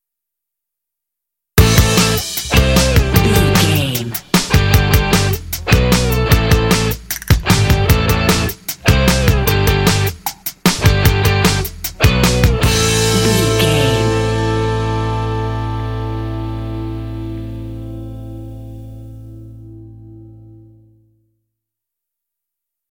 Fast paced
Aeolian/Minor
bouncy
groovy
drums
electric guitar
bass guitar